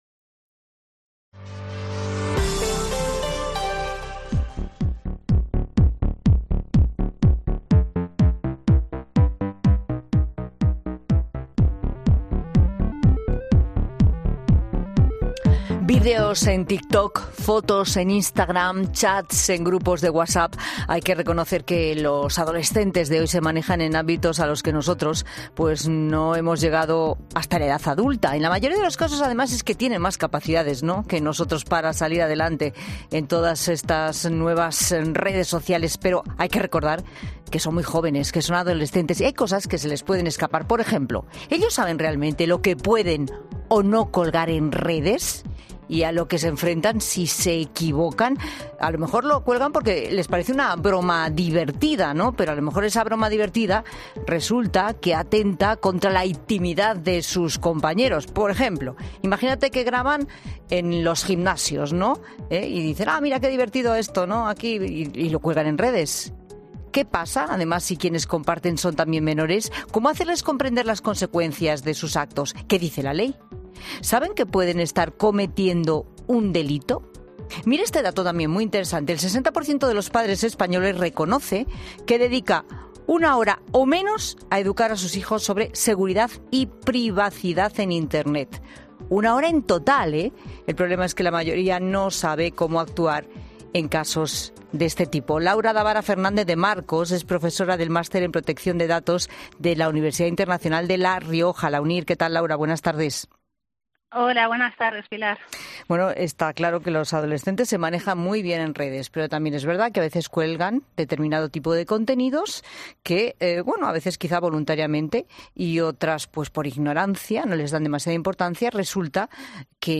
Puedes escuchar la entrevista completa aquí en La Tarde de COPE